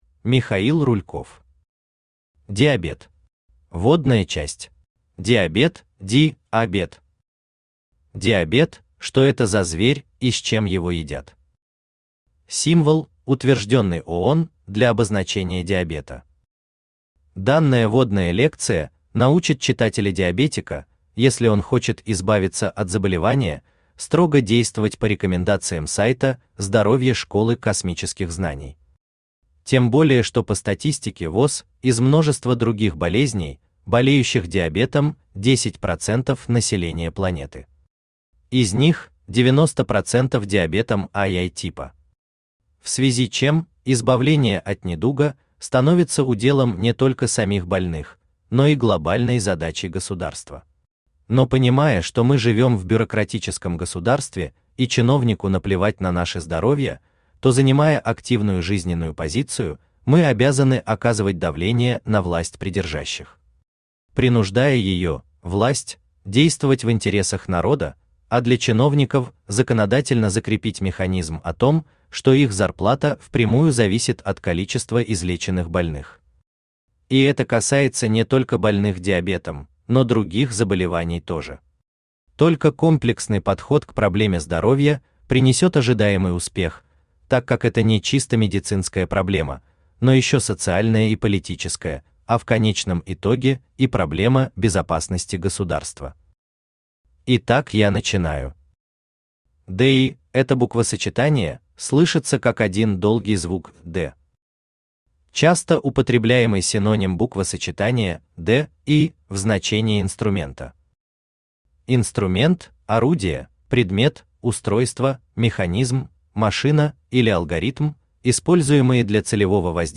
Аудиокнига Диабет | Библиотека аудиокниг
Aудиокнига Диабет Автор Михаил Михайлович Рульков Читает аудиокнигу Авточтец ЛитРес.